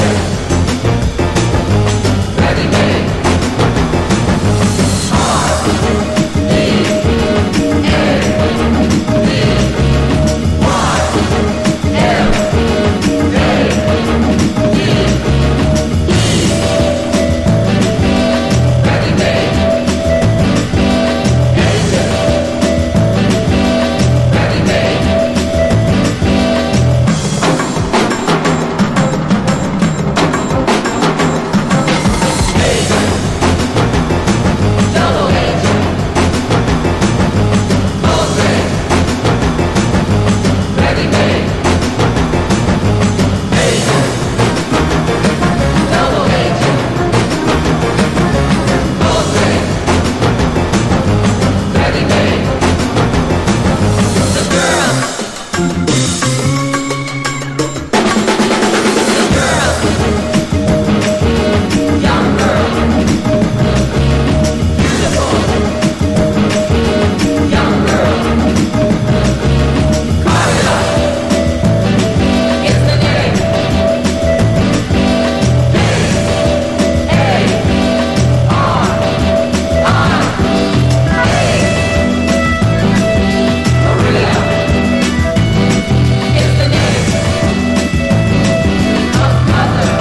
シネマティックなジャズA2もオススメ。